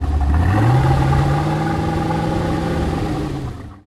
dviratel_gas_long.ogg